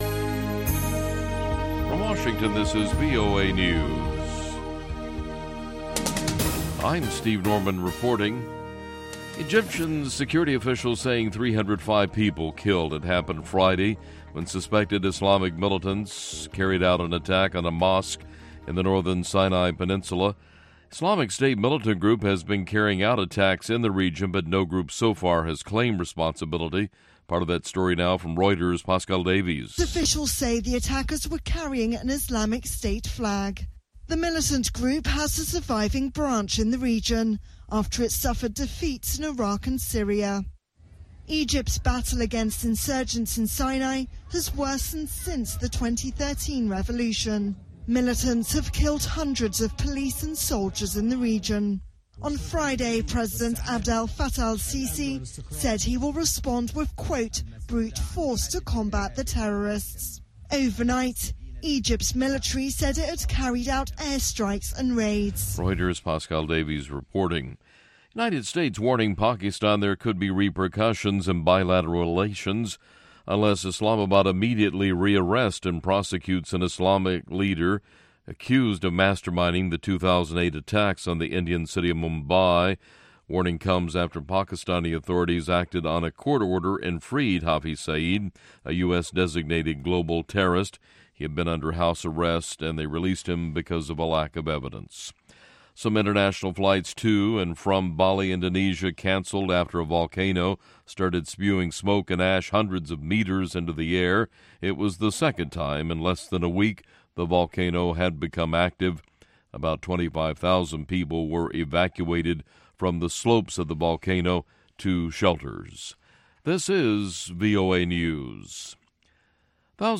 This episode of Music Time in Africa brings you a carefully crafted selection of 12 songs from across the African continent and the diaspora.